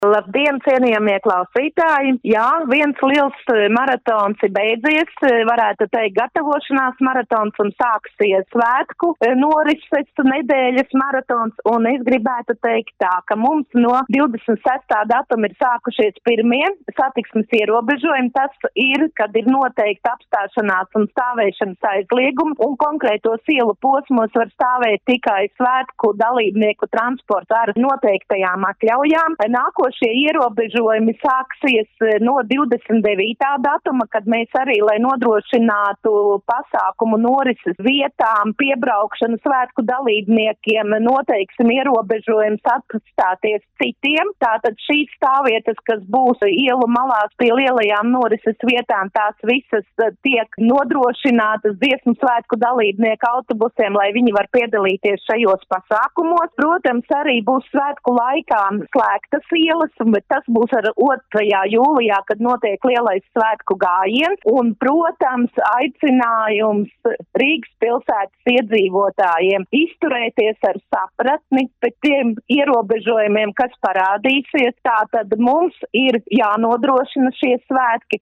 RADIO SKONTO Ziņās par satiksmes ierobežojumiem Rīgā Dziesmu un deju svētku laikā